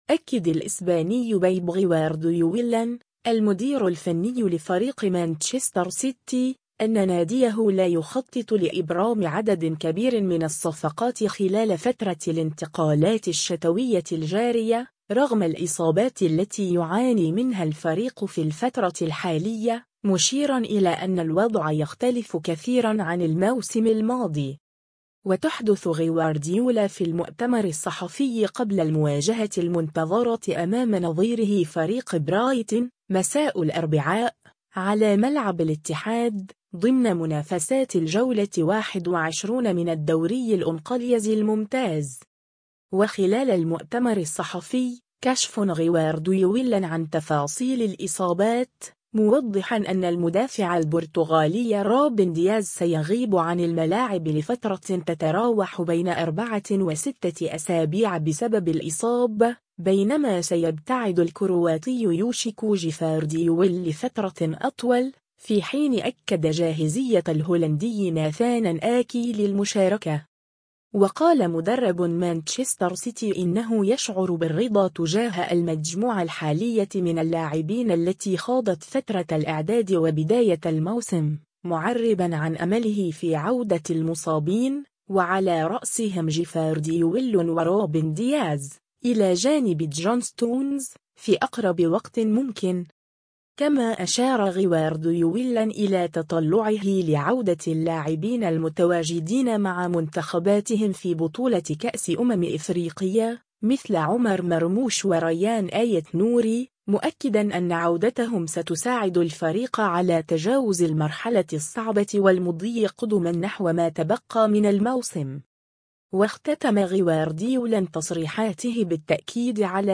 و تحدث غوارديولا في المؤتمر الصحفي قبل المواجهة المنتظرة أمام نظيره فريق برايتون، مساء الأربعاء، على ملعب “الاتحاد”، ضمن منافسات الجولة 21 من الدوري الإنقليزي الممتاز.